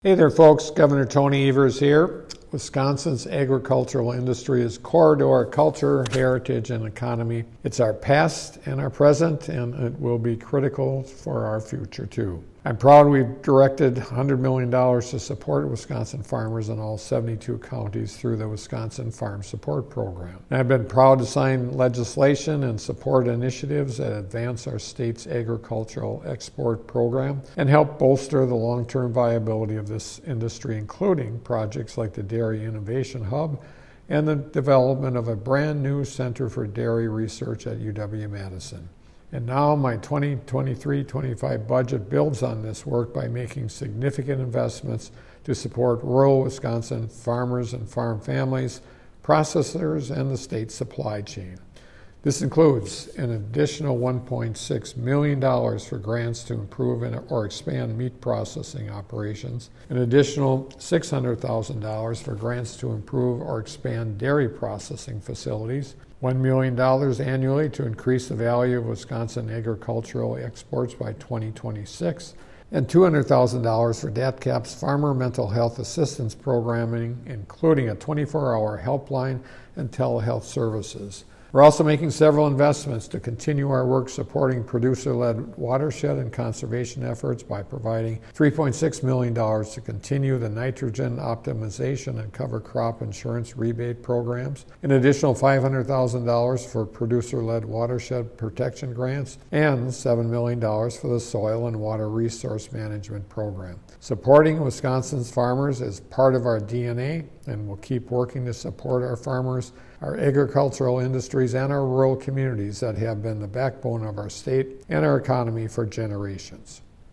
MADISON — Gov. Tony Evers today delivered the Democratic Radio Address on his 2023-25 biennial budget investments to support Wisconsin’s agricultural industries.